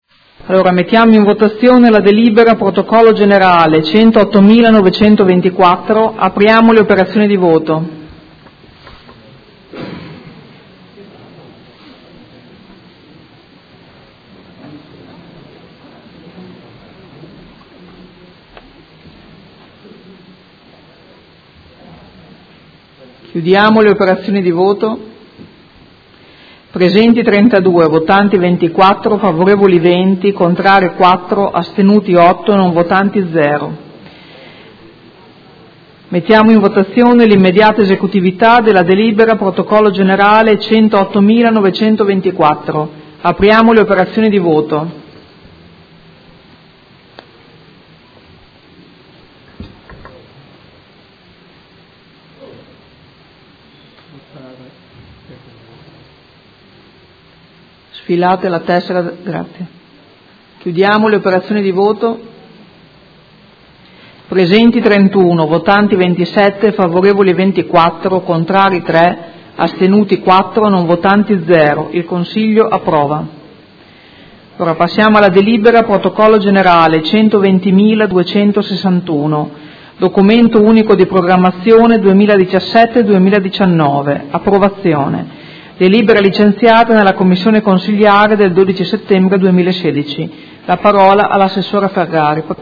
Seduta del 22/09/2016 Proposta di deliberazione: Bilancio 2016-2018, Programma Triennale dei Lavori Pubblici 2016- 2018 – Variazione di Bilancio n. 4. Votazione delibera ed immediata esecutività